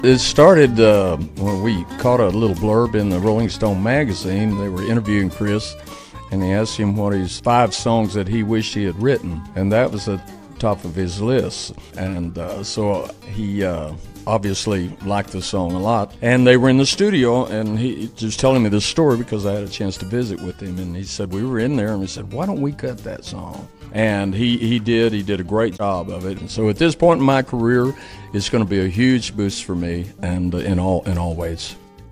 Gary talked to us recently on The Front Porch Show, and we asked him how this whole thing came about and how it felt to add this to a long list of accolades of a 40+ year career.